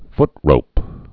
foot·rope
(ftrōp)